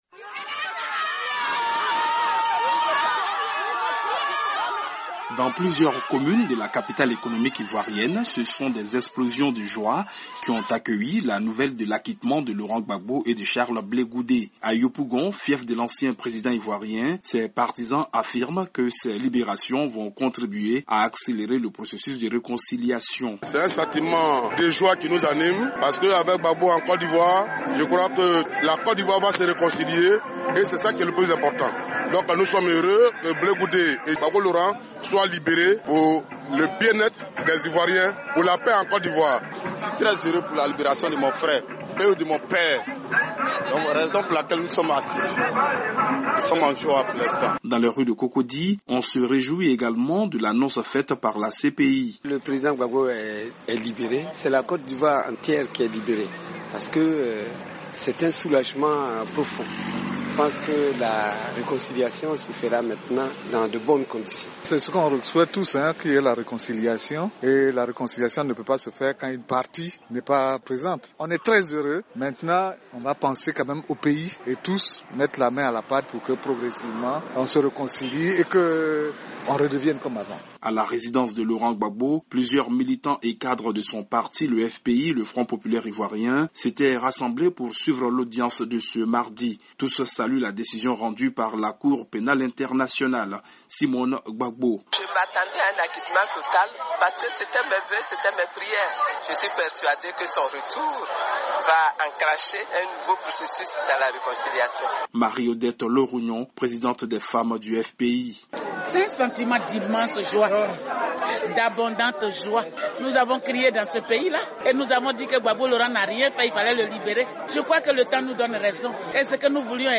L'analyse de la libération de Gbagbo par notre correspondant